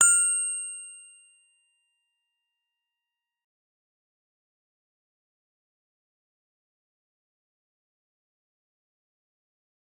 G_Musicbox-F6-f.wav